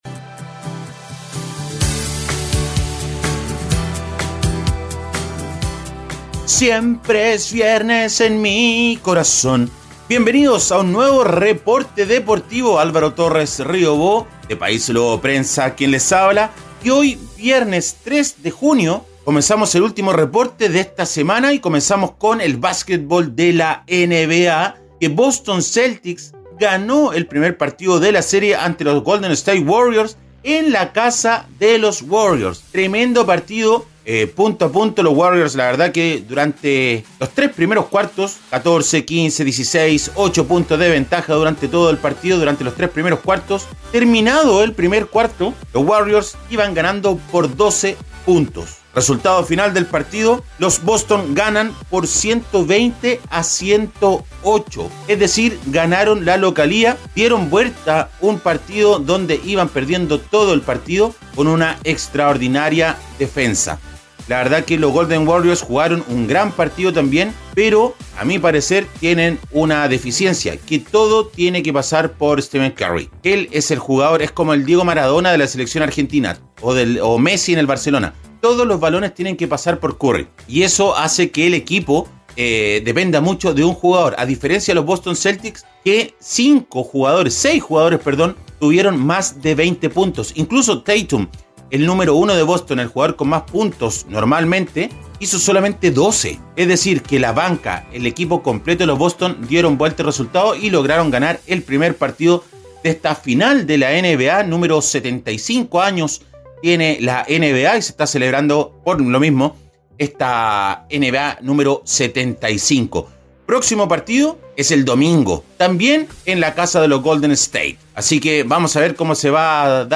Reporte Deportivo ▶ Podcast 03 de junio de 2022